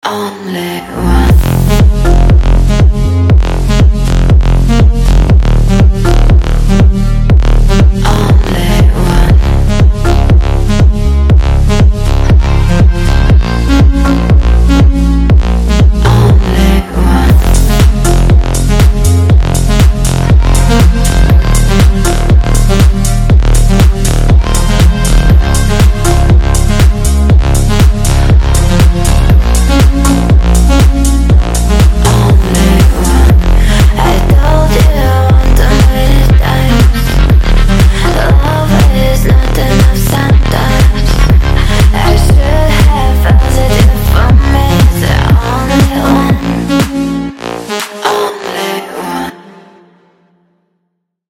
Танцевальные рингтоны
Громкие рингтоны